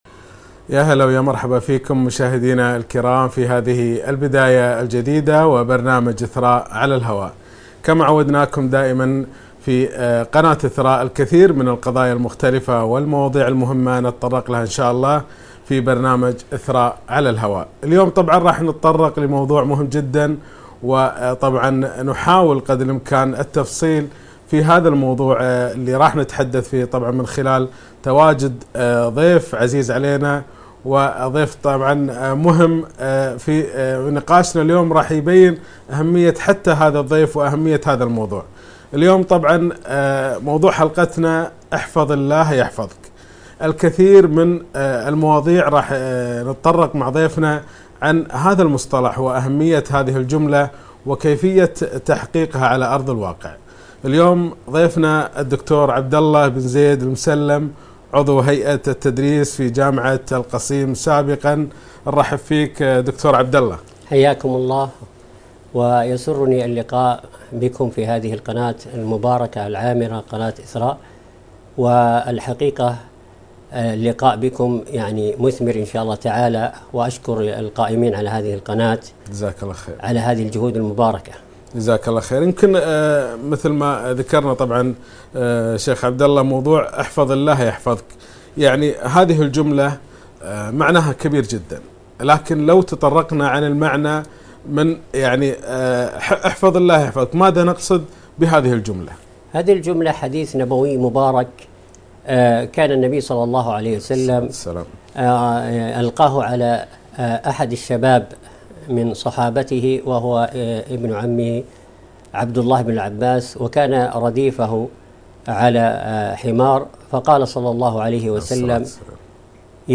لقاء قناة إثراء برنامج إثراء على الهواء - حلقة بعنوان احفظ الله يحفظك